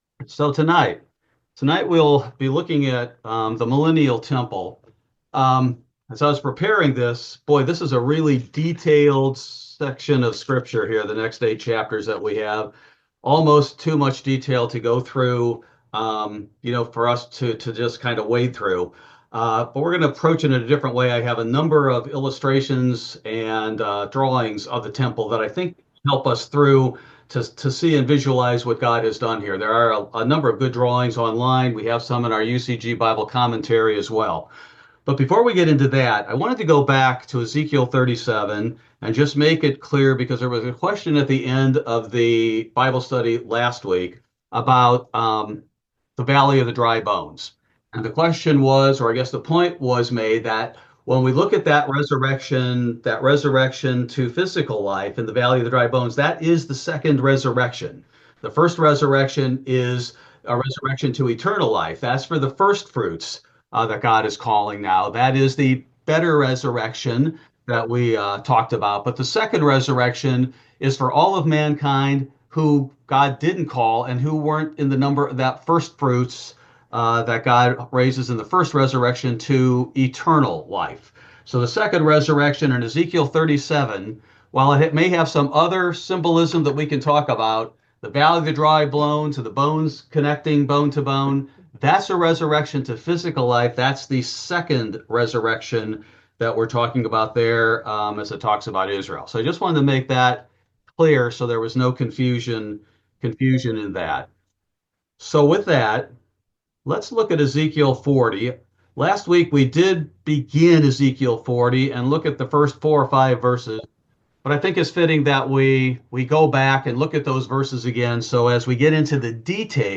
Ezekiel Bible Study: March 26, 2025